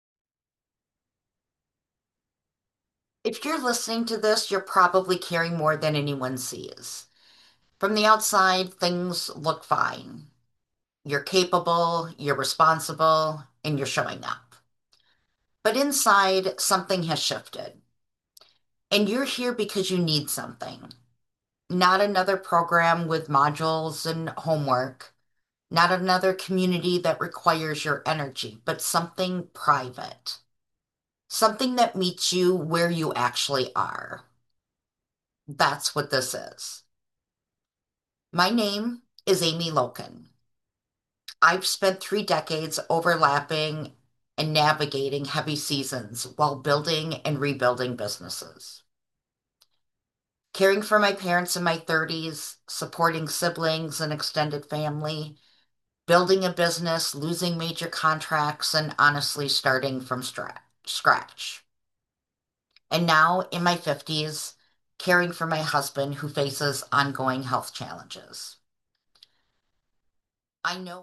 This is private, intentional audio you can return to in your own time when you need something real, steady, and honest.
I recorded this experience to feel personal, direct, and honest.
Not overproduced.
Just steady words, shared with intention.